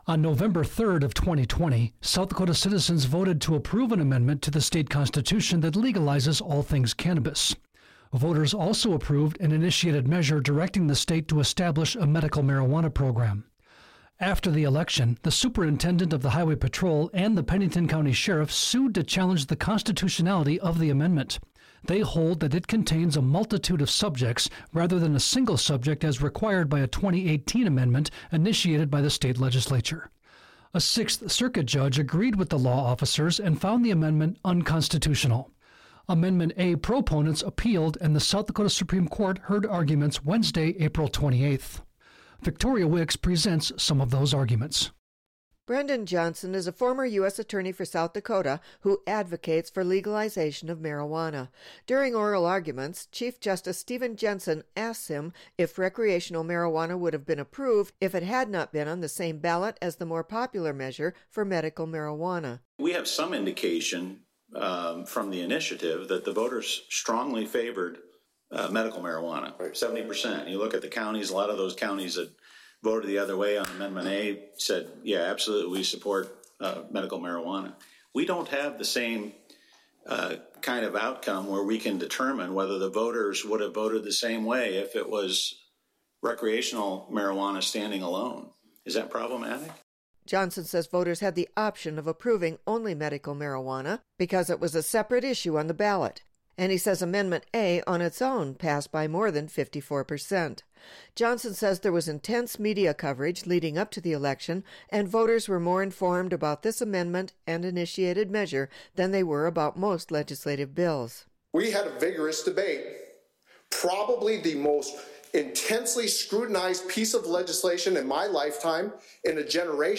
During oral arguments, Chief Justice Steven Jensen asks him if recreational marijuana would have been approved if it had not been on the same ballot as the more popular measure for medical marijuana.
Justice Janine Kern addresses that concern with Brendan Johnson.